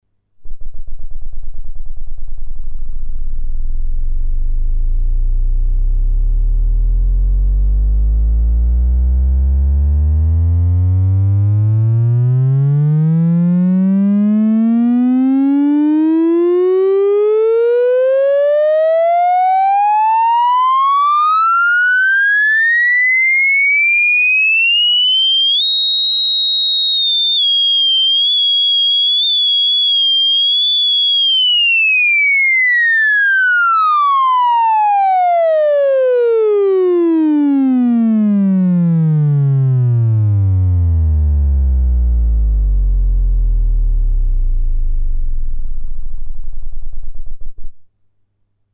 Терменвокс - 2.- буфер на SN74HC02N
• Категория: Theremin
• Качество: Высокое